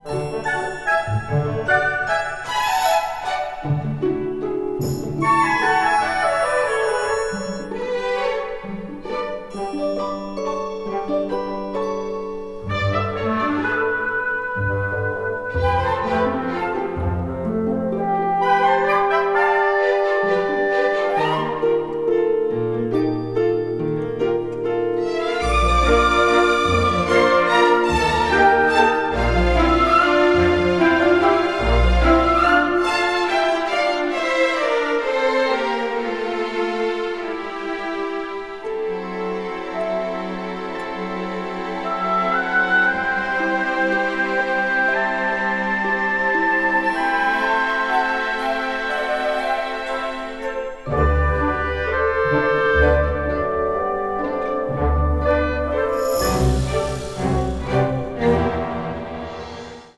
exciting, classic symphonic adventure score